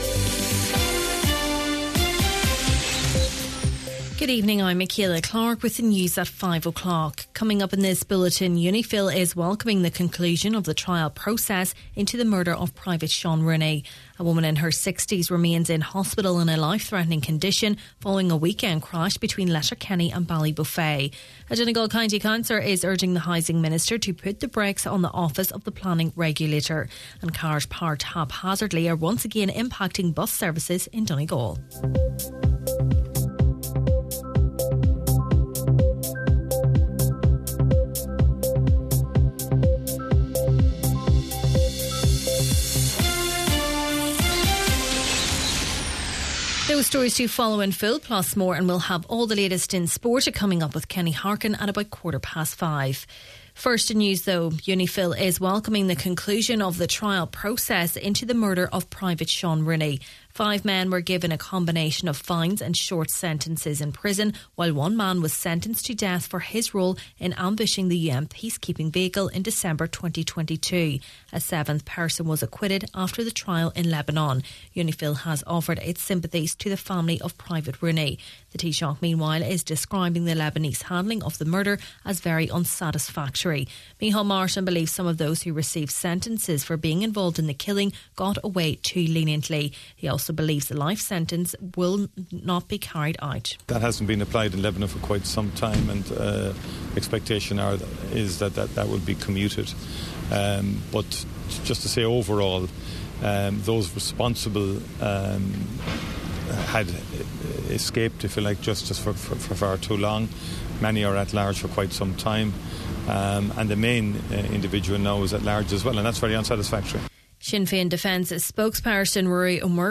Main Evening News, Sport and Obituaries – Tuesday, July 29th